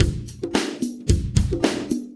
Index of /90_sSampleCDs/USB Soundscan vol.46 - 70_s Breakbeats [AKAI] 1CD/Partition B/26-110LOOP A